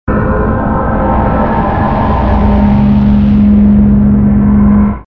cloak_rh_cruiser.wav